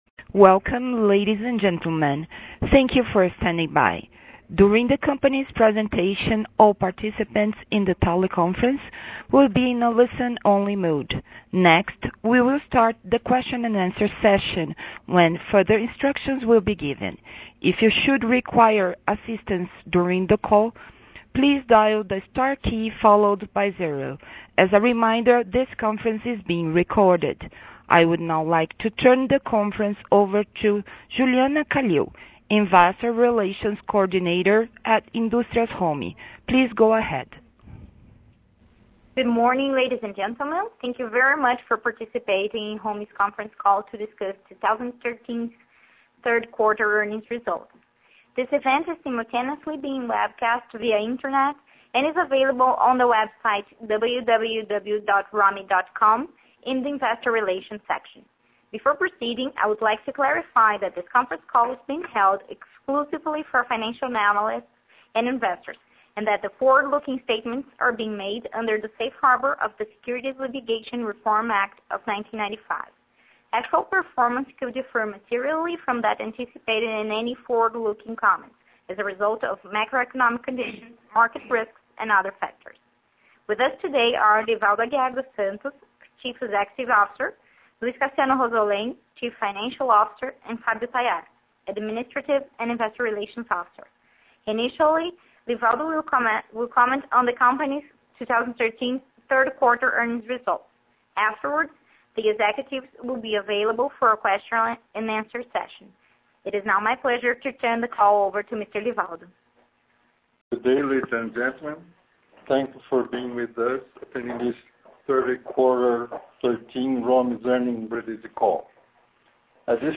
Audio from Quarter Teleconference